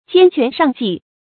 兼权尚计 jiān quán shàng jì 成语解释 指全面衡量，深思熟虑 成语出处 荀况《荀子 不苟》：“兼权之，孰计之，然后定其欲恶取舍。”